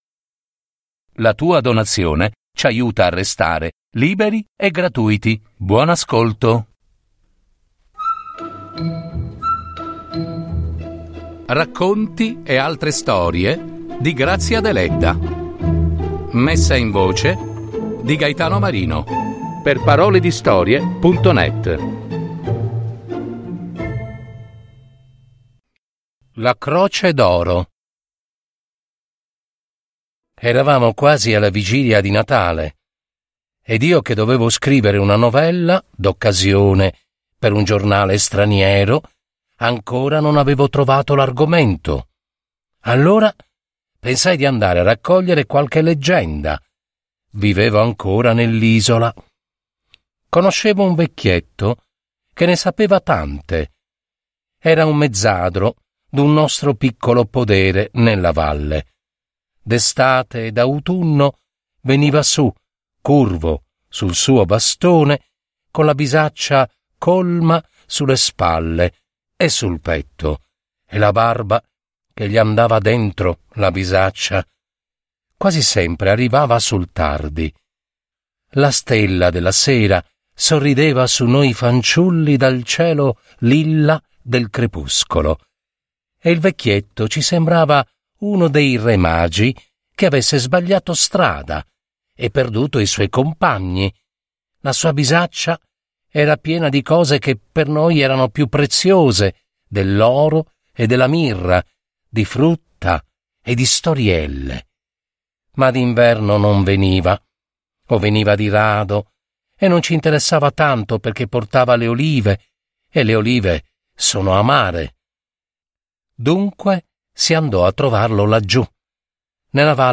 La croce d’oro. Un racconto di Grazia Deledda